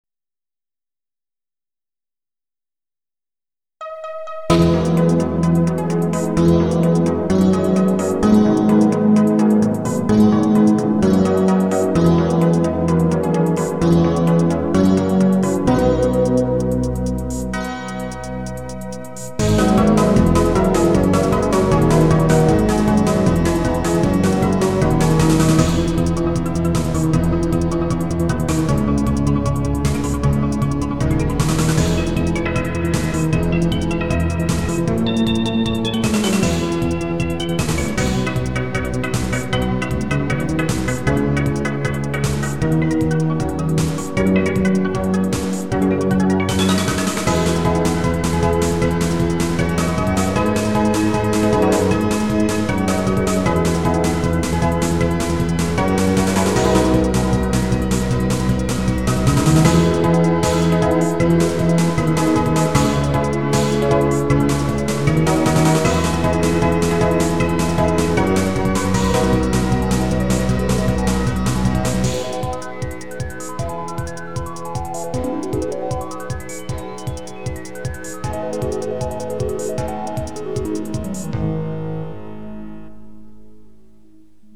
ストイックな正統派ＲＰＧの戦闘をイメージしたいわゆる
この頃は、ドラムも１音符づつ手打ちしてたのです。
XG音源で聴くとエフェクトと音色が綺麗です。
XG音源でのmp3は